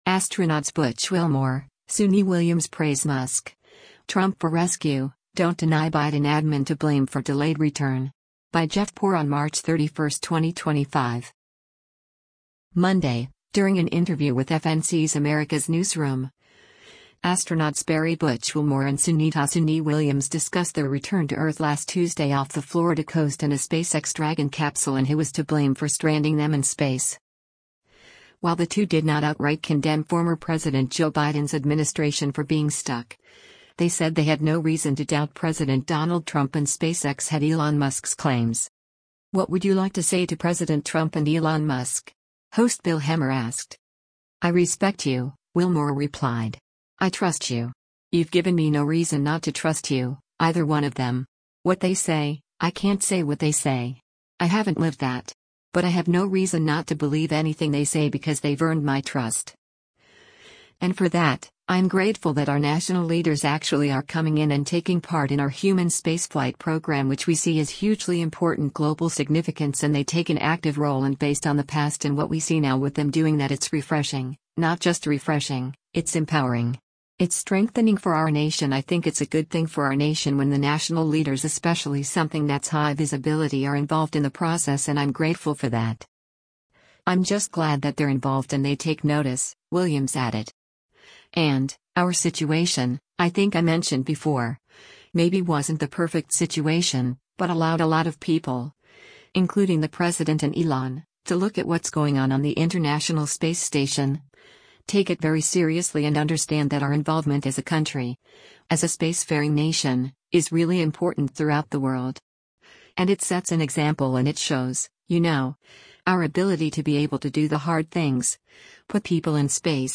Monday, during an interview with FNC’s “America’s Newsroom,” astronauts Barry “Butch” Wilmore and Sunita “Suni” Williams discussed their return to Earth last Tuesday off the Florida coast in a SpaceX Dragon capsule and who was to blame for stranding them in space.
“What would you like to say to President Trump and Elon Musk?” host Bill Hemmer asked.